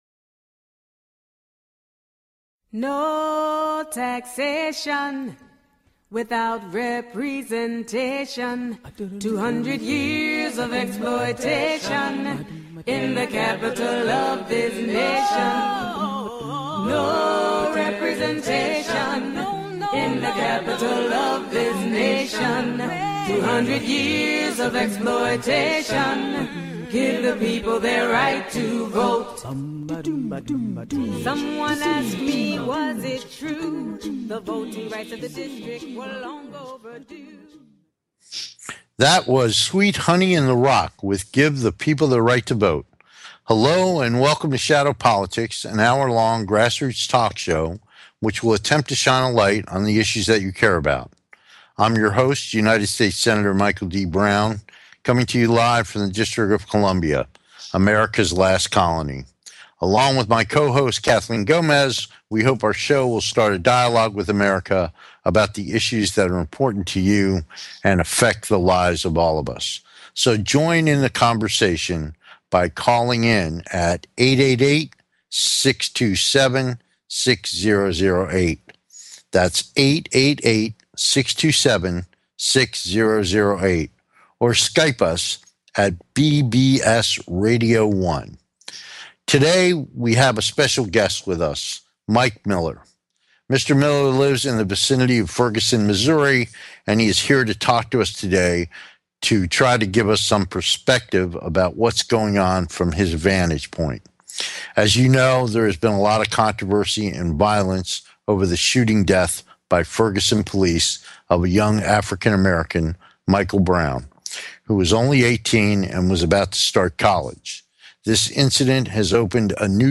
Shadow Politics is a grass roots talk show giving a voice to the voiceless.